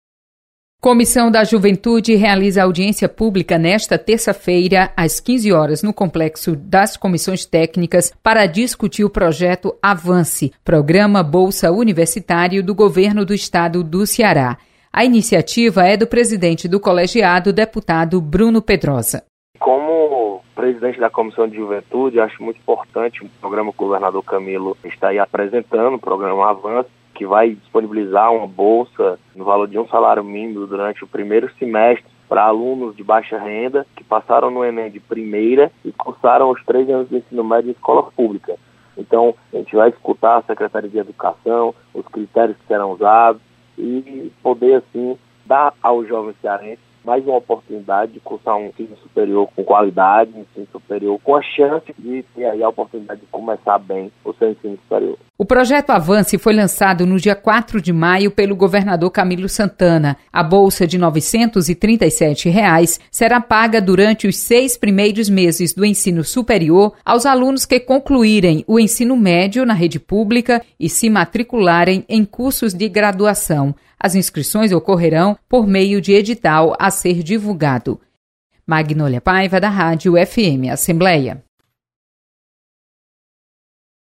Projeto Avance do Governo do Estado será debatido na Assembleia Legislativa. Repórter